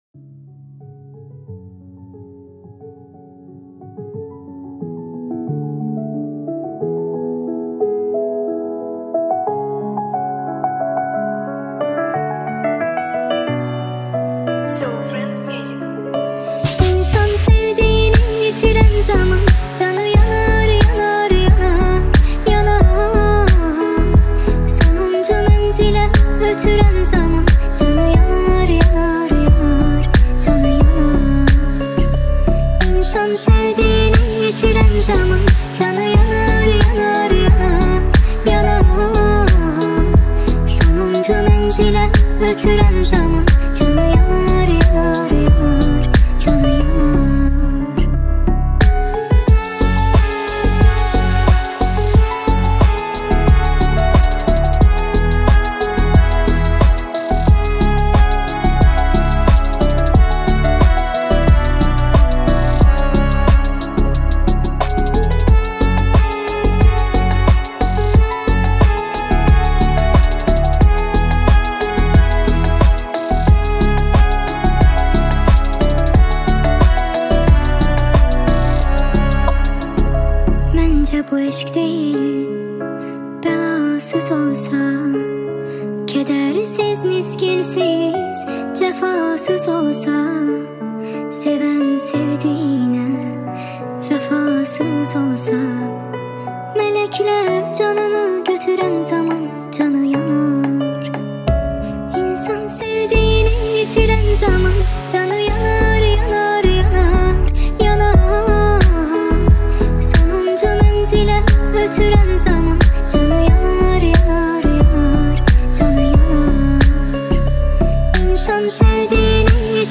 ریمیکس با صدای بچه